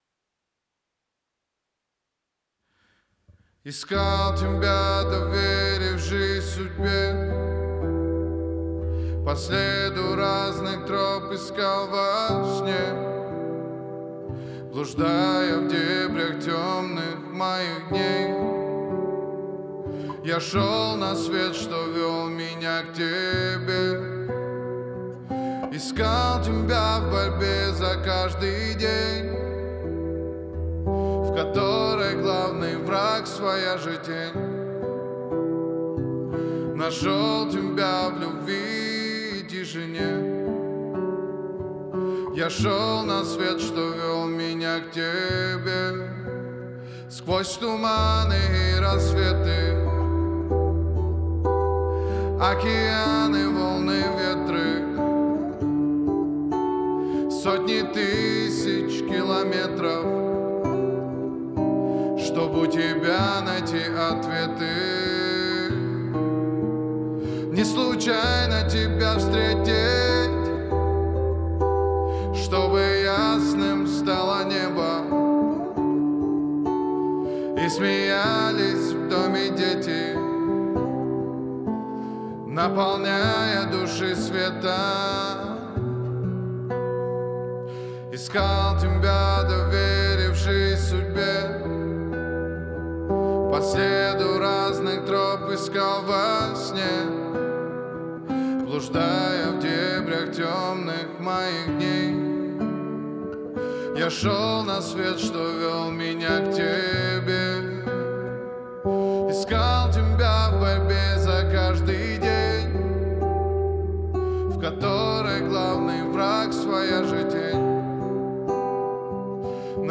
• Категория: Русские треки